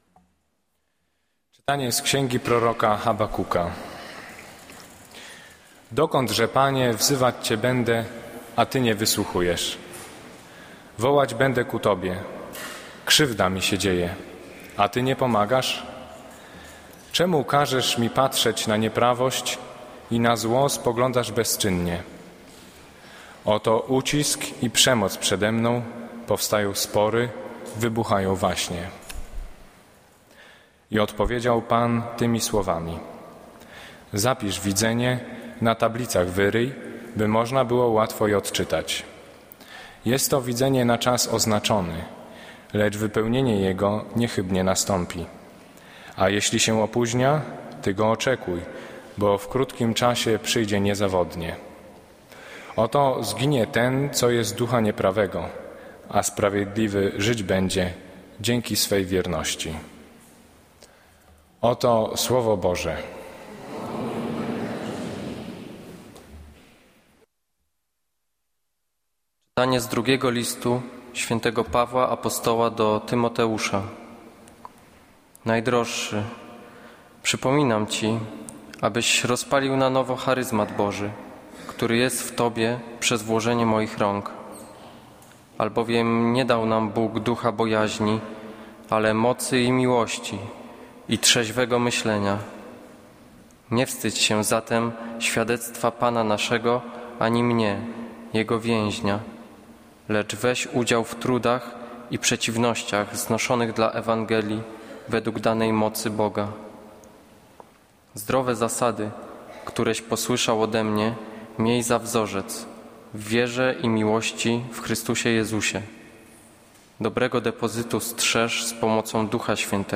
Kazanie z 6 października 2013r.
Piotra Pawlukiewicza // niedziela, godzina 15:00, kościół św. Anny w Warszawie « Kazanie z 23 czerwca 2013r.